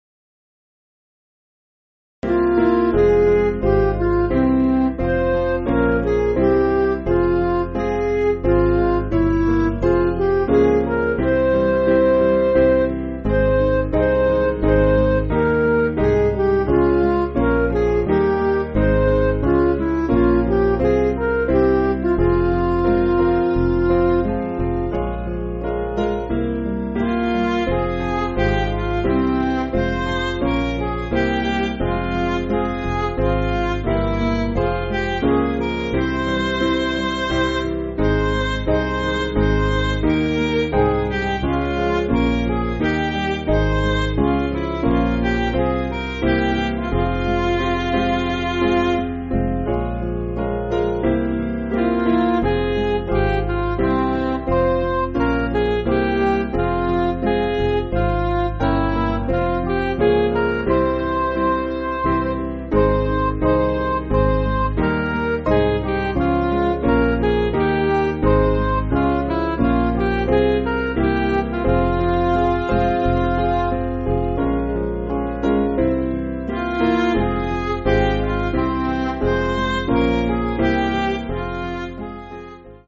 Piano & Instrumental
(CM)   5/Fm